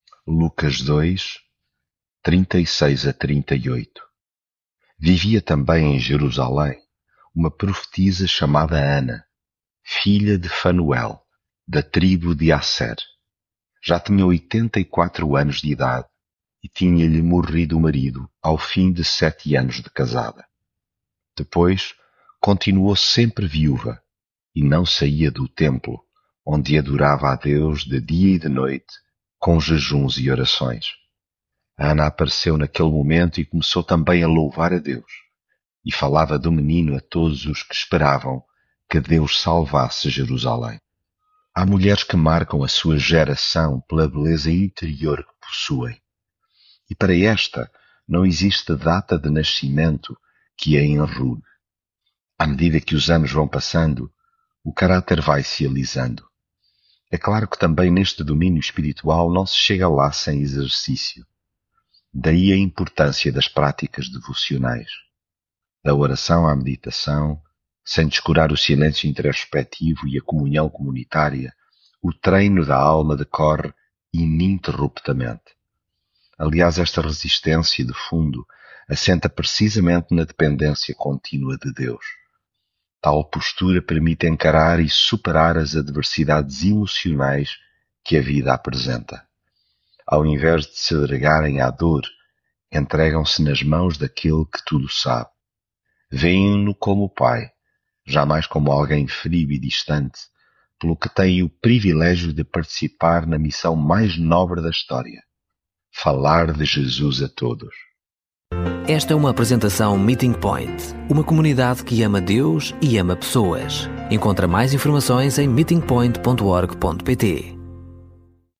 devocional Lucas leitura bíblica Vivia também em Jerusalém uma profetisa chamada Ana, filha de Fanuel, da tribo de Asser.